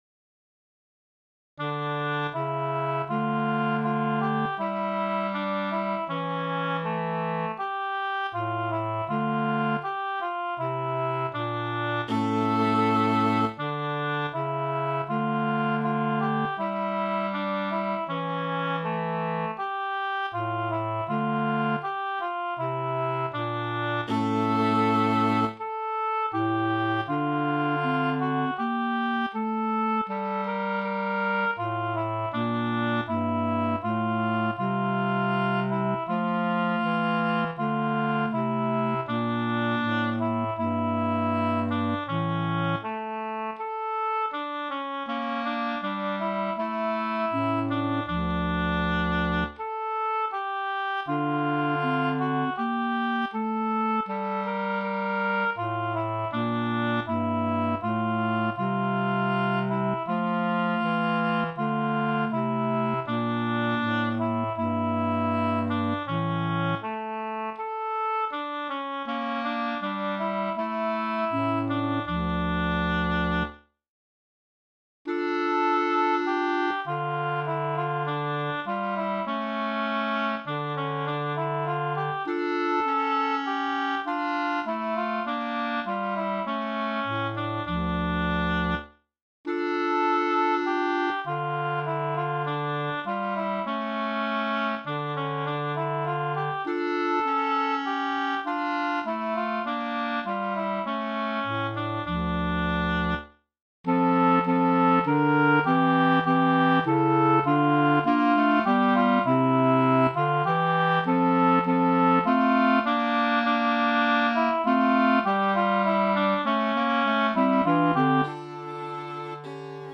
Muziko:
Svito (serio de dancaĵoj) de Roberto de Viseo.